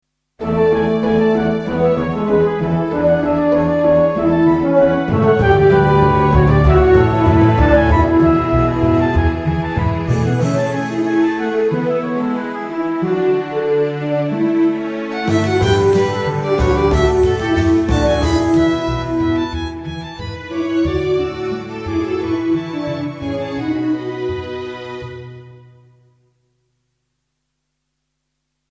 intromuziek met hoogtepunt in het midden